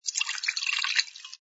sfx_drinks_pouring04.wav